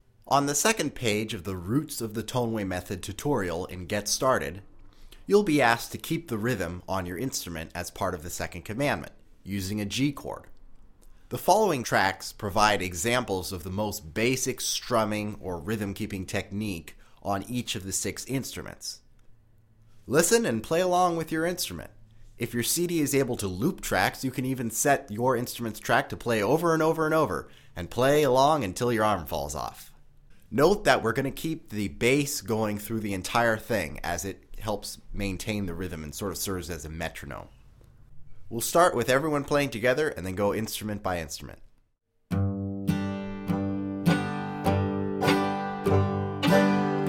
Slow version, chorus only (key of D)